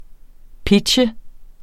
Udtale [ ˈpidɕə ]